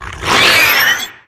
~attack_hit_2.ogg